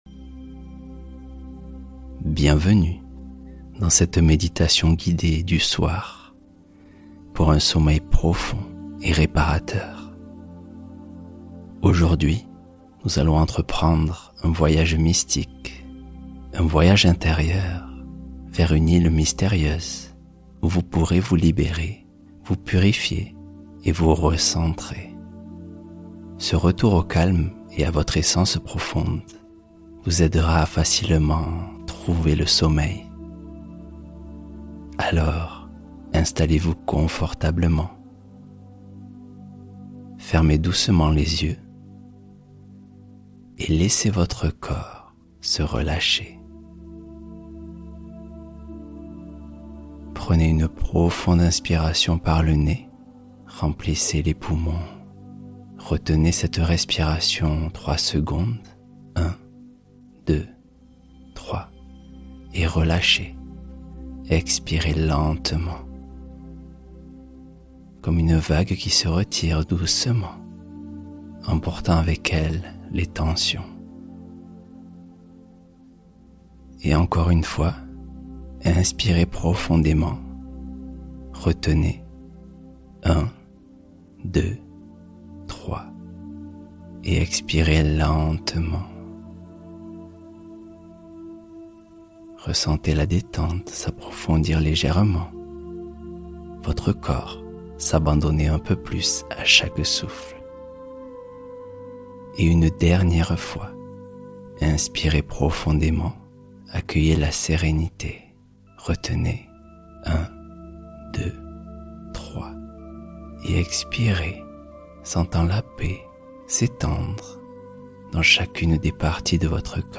Rituel du Soir : Pratique guidée pour un endormissement profond